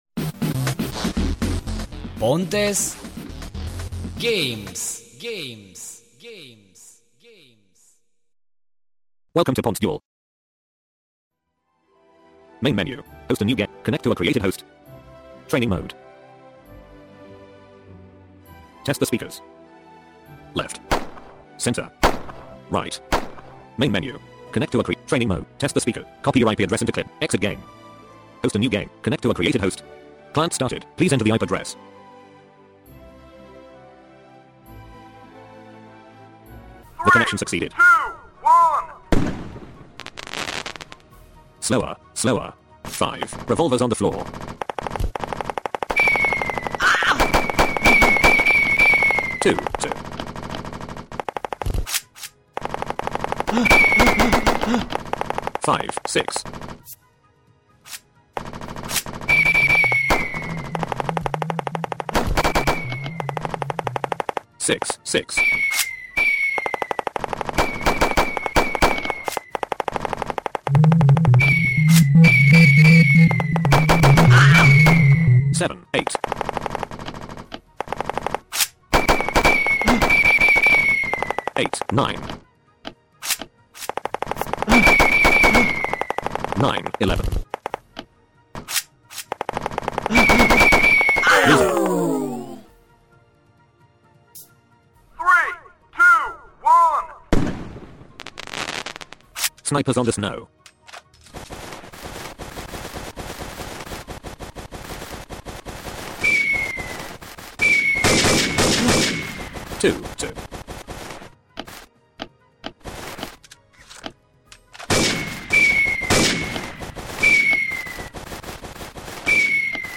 Cei doi se pot auzi reciproc cu toate miscarile lor, reincarcare, lovire de margini, fiecare pas, impuscatura, lovitura, ratare sau nimerire etc.
Sunetele adversarului se aud mai departe, cele proprii mai aproape.
pontes-duel-1.1-demonstration.mp3